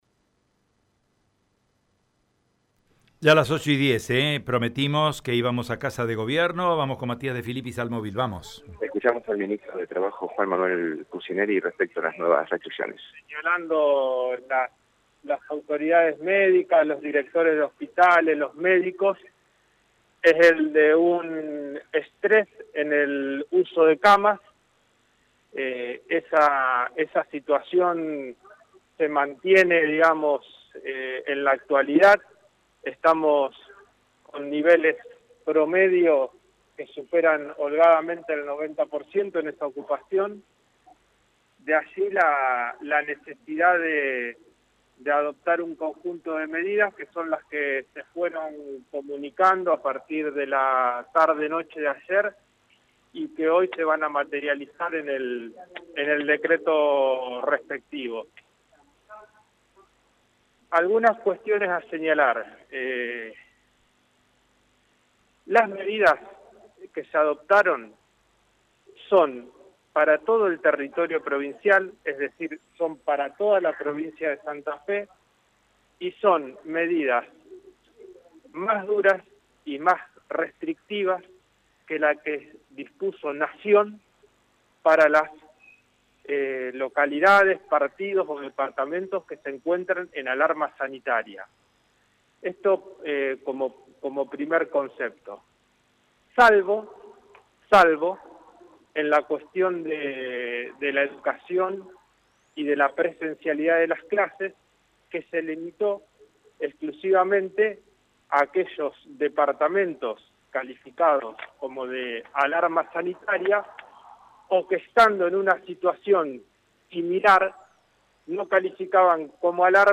conferencia-de-prensa-Ministro-de-Trabajo-Empleo-y-Seguridad-Social-Juan-Manuel-Pusineri.mp3